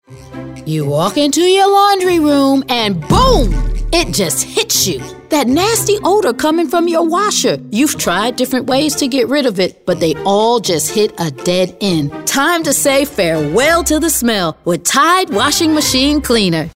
anti-announcer, confident, genuine, humorous, retail, upbeat, young adult